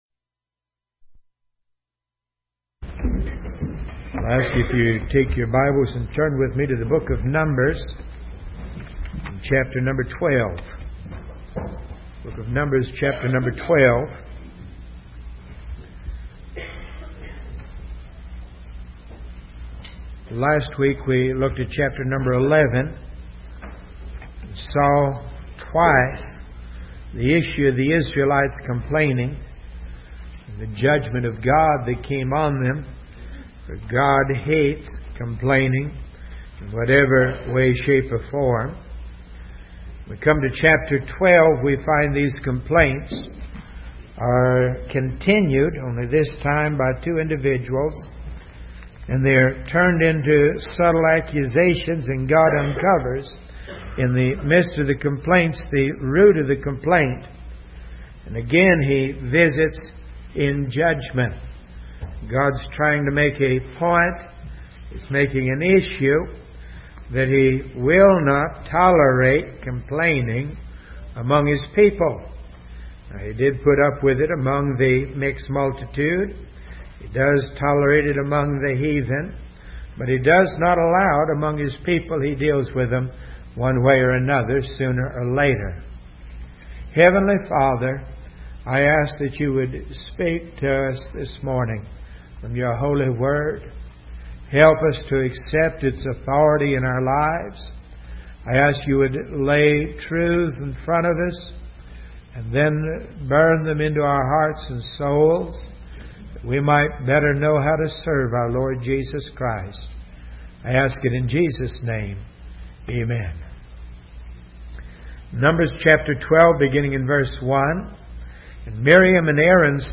Test Sermons